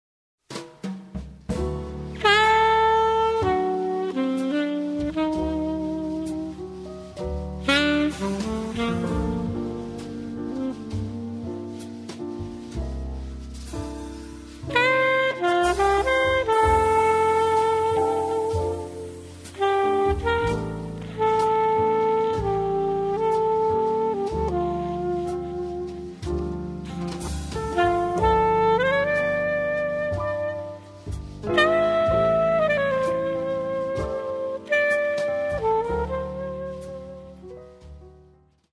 sax alto
chitarra elettrica
piano
contrabbasso
batteria
ballad